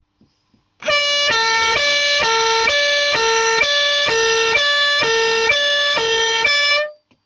Fisa avertisseur Impulsound 60 FPI2C 12V | 146196
Fisa avertisseur Impulsound 60 FPI2C 12VSpécifications:- Son de sirène- 118dB- 200W- 560Hz high tone- 460Hz low tone- 60 cycles par minute